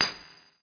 stonecutter1.mp3